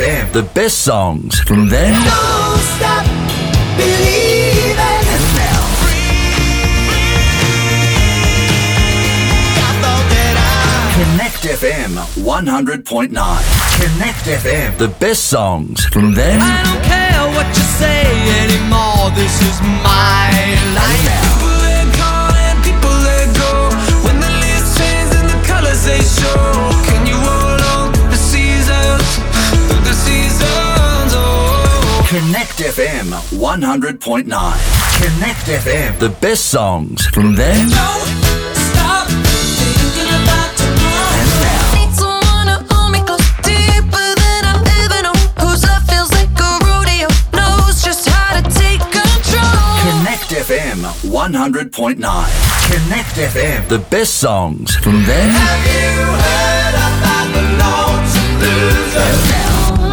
Male
My accent is Australian English.
Radio / TV Imaging
Words that describe my voice are Australian, Narrator, Voice over.